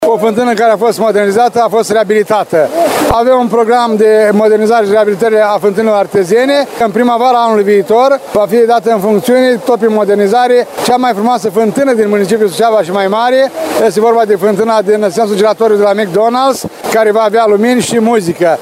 Prezent la inaugurare, primarul ION LUNGU a declarat că municipalitatea va continua modernizările în acest domeniu.